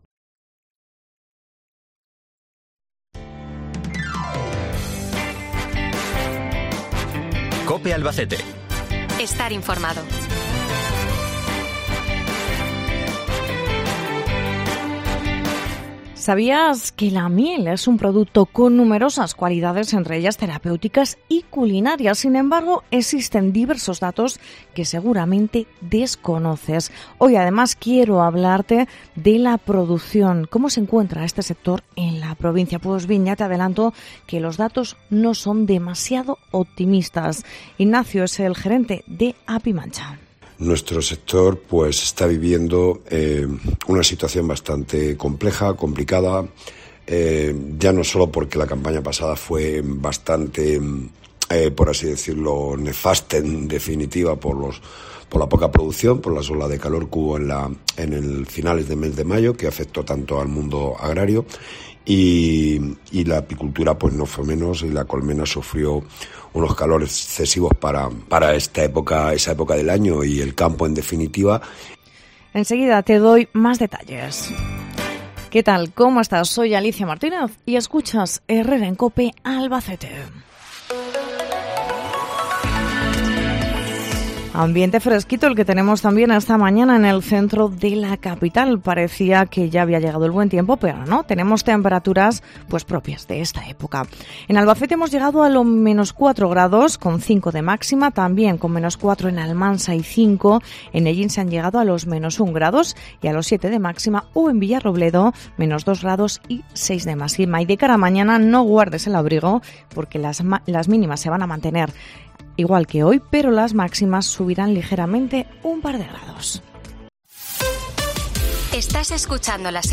Miel Albacete Puedes escuchar la entrevista completa aquí: 00:00 Volumen Descargar Herrera en COPE Albacete a las 12:50 - 27 de febrero -